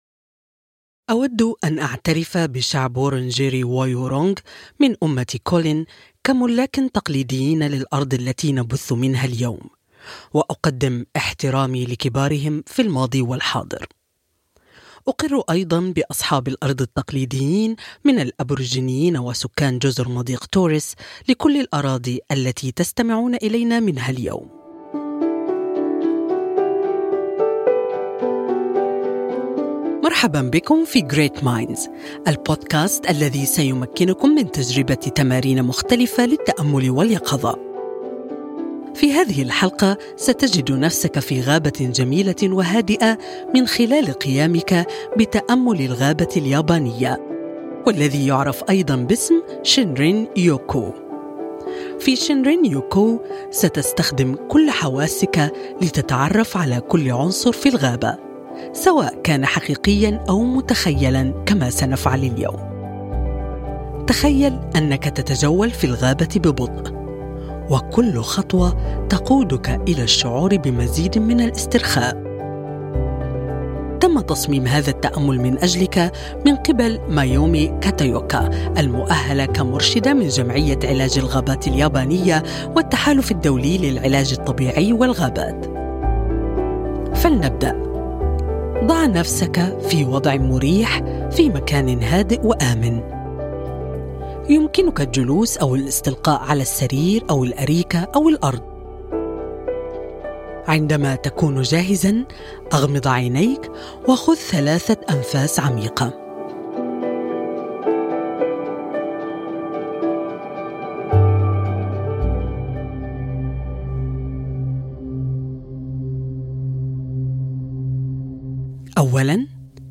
Shinrin-yoku هي عبارة يابانية تعني "الاستحمام في الغابة". في تمرين التأمل هذا ، ستقوم برحلة متخيلة عبر غابة جميلة وهادئة.